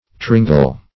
tringle - definition of tringle - synonyms, pronunciation, spelling from Free Dictionary Search Result for " tringle" : The Collaborative International Dictionary of English v.0.48: Tringle \Trin"gle\, n. [F. tringle.]
tringle.mp3